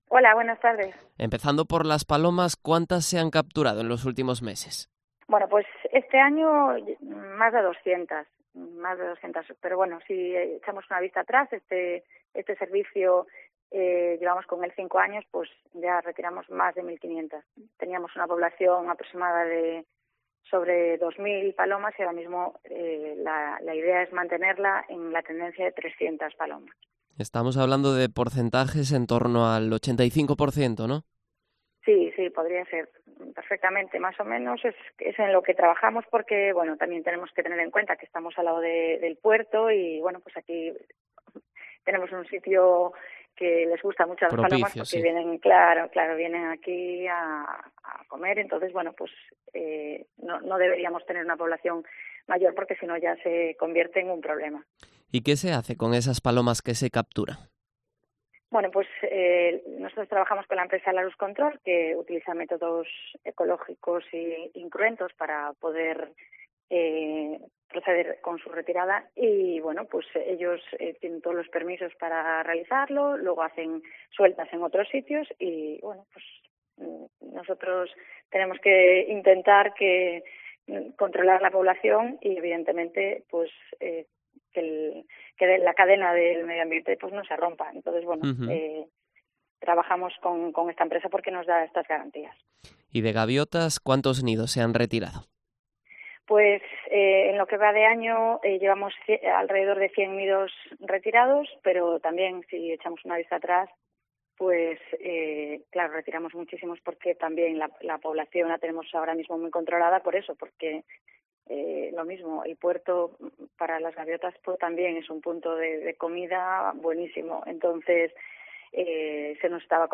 AUDIO: Entrevista a Marián Sanmartín, concejala de Medio Ambiente de Marín